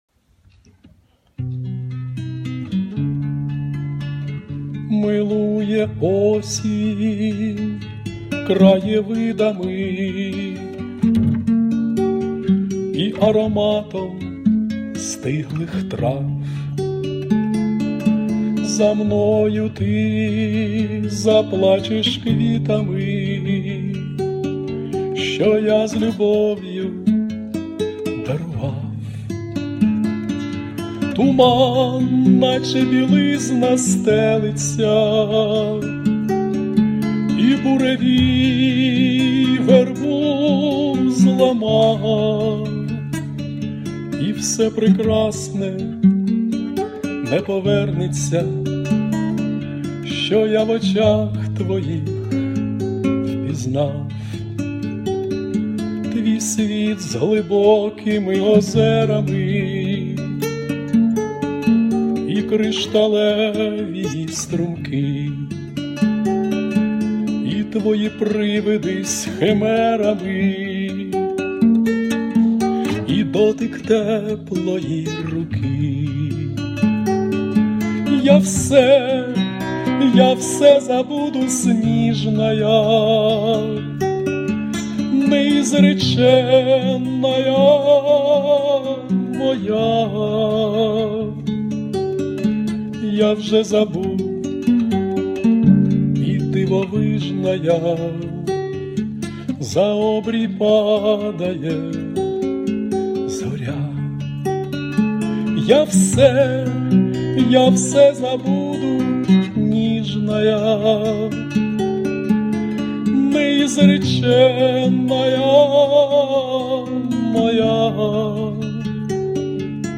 І пісня - душевна! 16 osen1 17 osen1